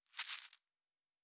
Fantasy Interface
Coins 32.wav